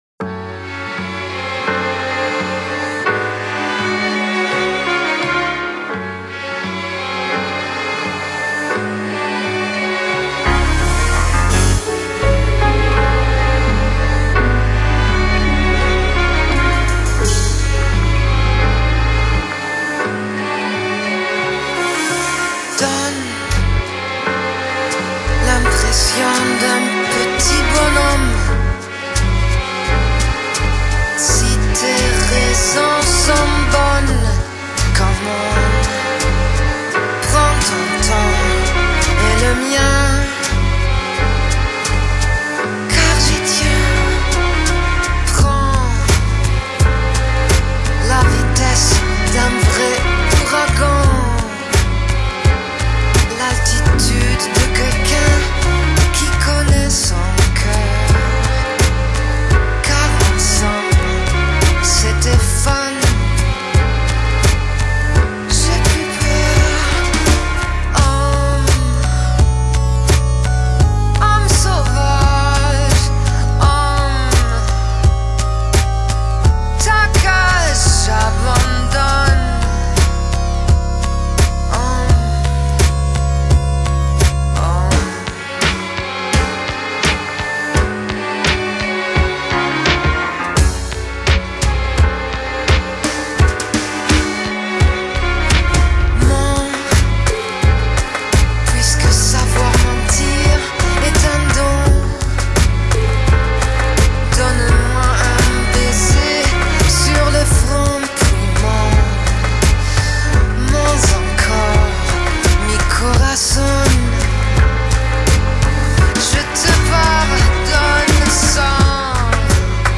Genre: Lounge, Downtempo, Lo-Fi, Acid Jazz, Deep House